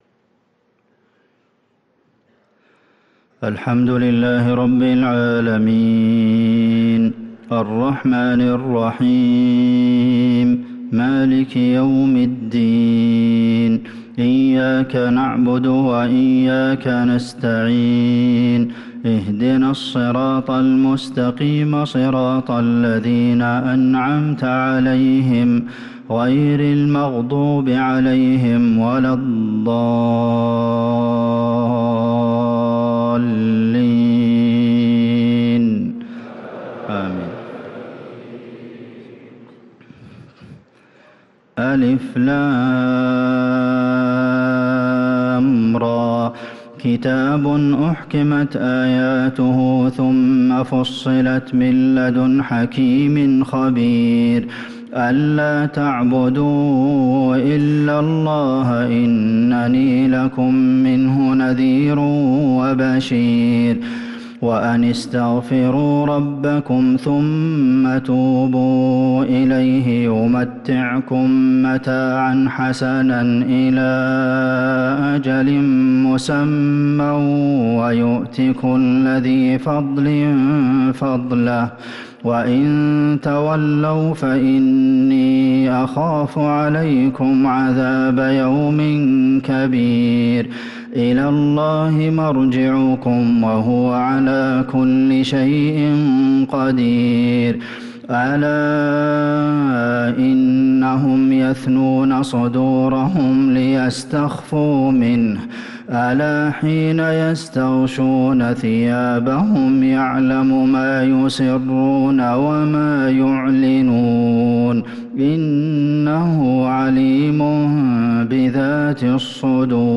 صلاة العشاء للقارئ عبدالمحسن القاسم 23 جمادي الآخر 1445 هـ
تِلَاوَات الْحَرَمَيْن .